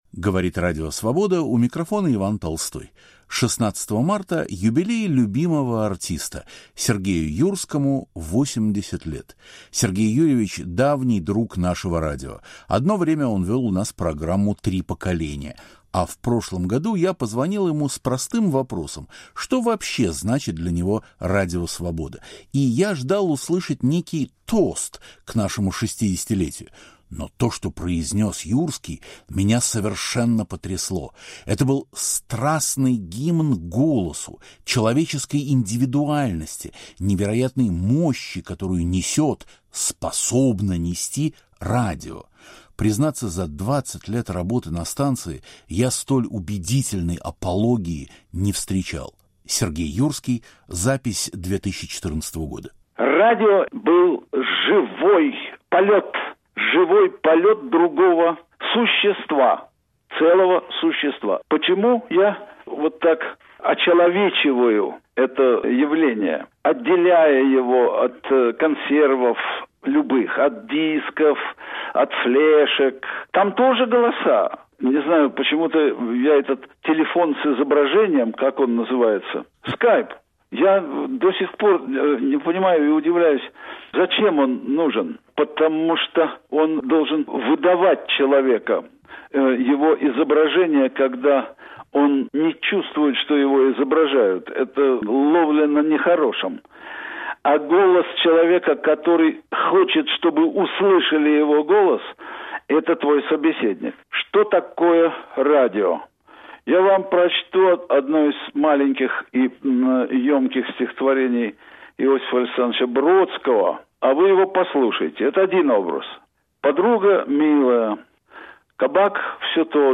Сегодня – размышления Юрского о театре, кино, политике и обществе. Записи из архива Свободы.